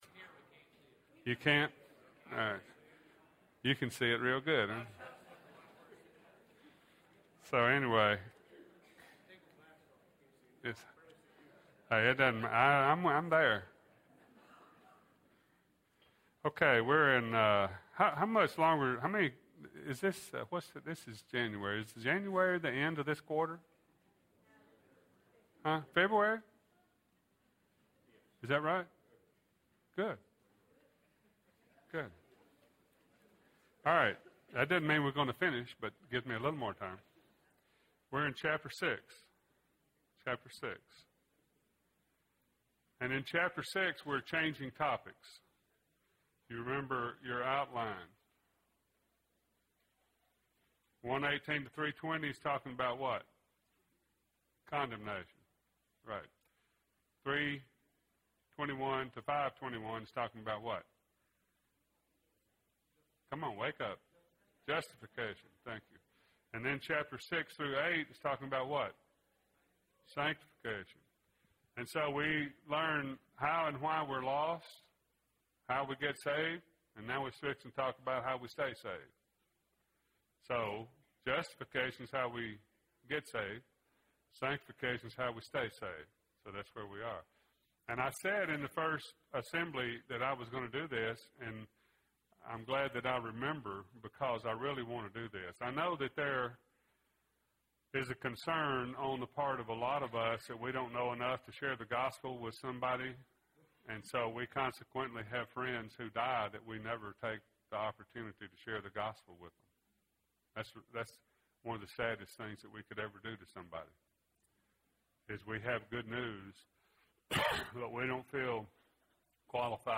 Romans – Shall We Keep Sinning?” (15 of 24) – Bible Lesson Recording